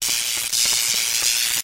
Electricty.wav